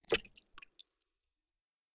Bullet Shell Sounds
generic_water_7.ogg